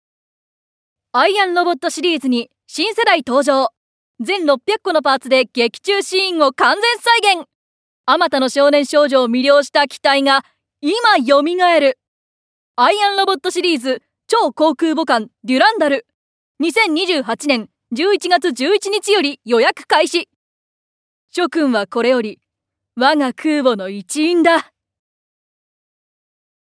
◆名乗り+市町村PRCM◆
◆商品説明会MC◆
◆男児向けおもちゃCM◆